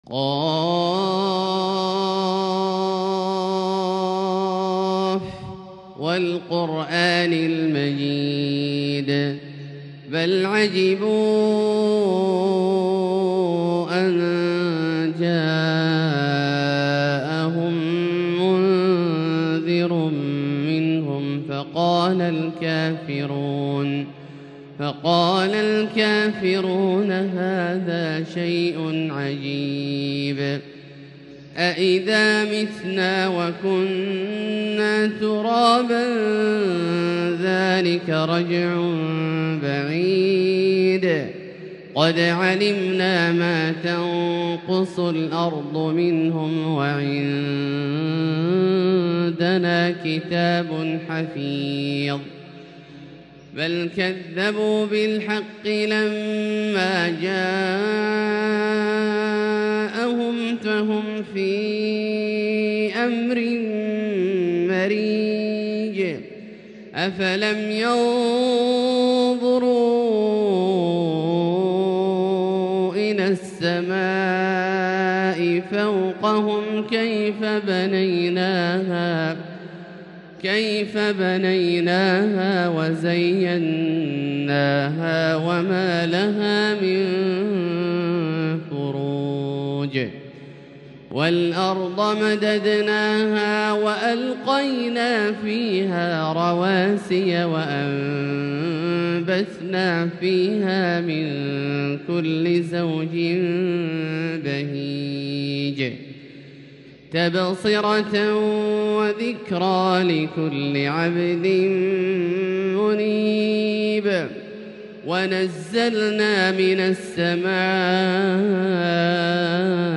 تلاوة مبكية خاشعة ولجت للقلوب لـ سورة ق كاملة للشيخ د. عبدالله الجهني من المسجد الحرام | Surat Qaf > تصوير مرئي للسور الكاملة من المسجد الحرام 🕋 > المزيد - تلاوات عبدالله الجهني